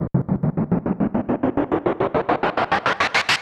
MB Trans FX (16).wav